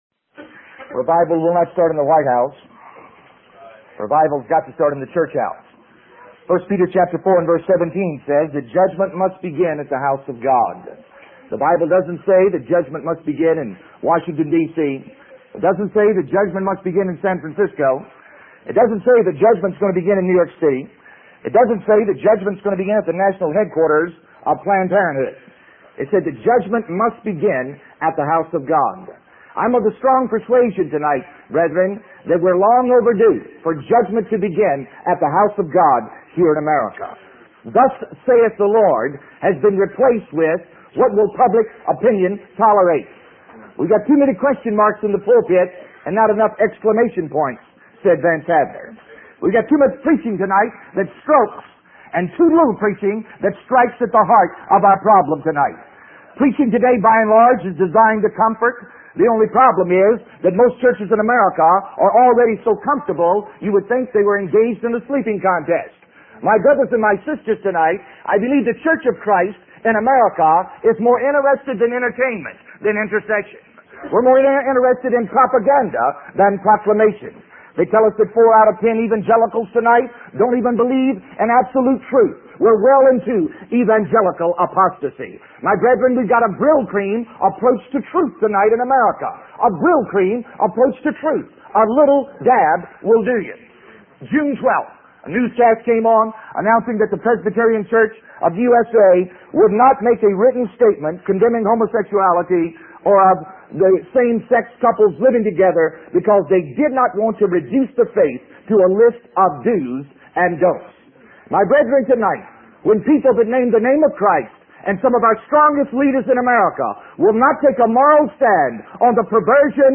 In this sermon, the preacher discusses the story of bears in Yellowstone Park to illustrate how people can lose sight of their purpose. He questions whether we have lost the reason for our existence and forgotten that we are here for the glory of God. The preacher emphasizes the importance of respectability in daily life and the need for strong families built on relevant discipleship.